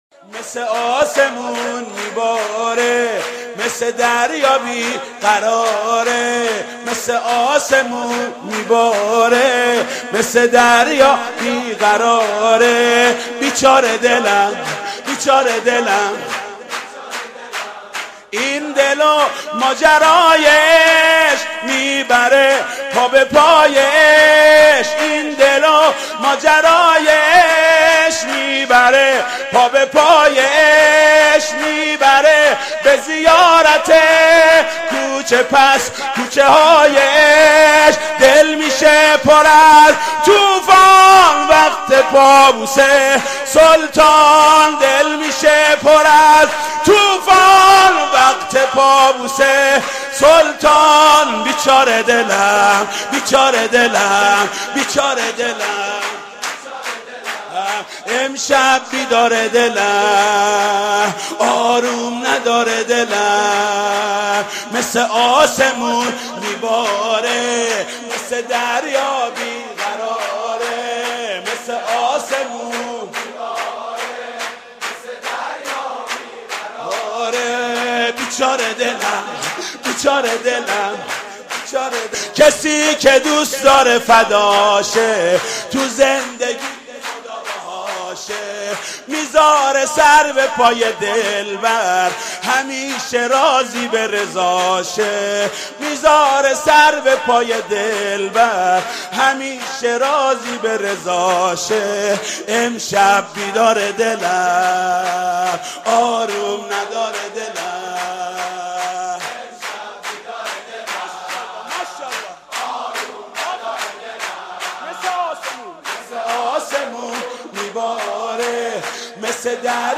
«میلاد امام رضا 1385» سرود: مثل آسمون می باره